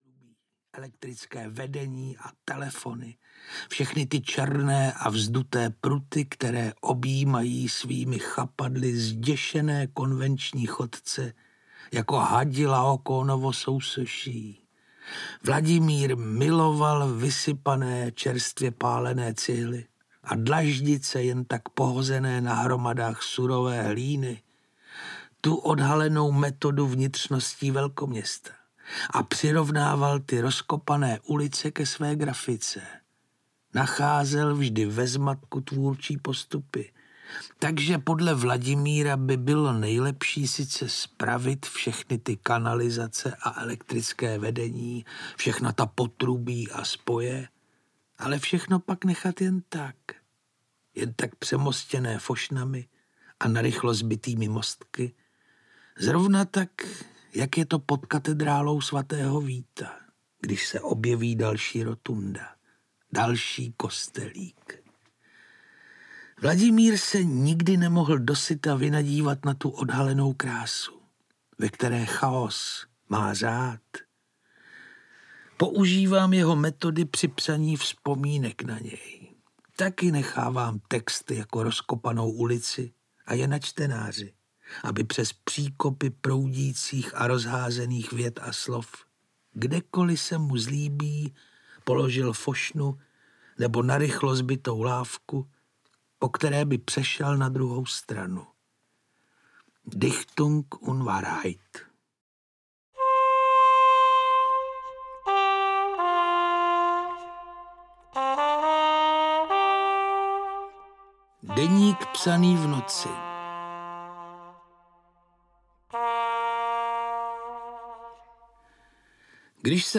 Něžný barbar audiokniha
Ukázka z knihy
• InterpretPetr Čtvrtníček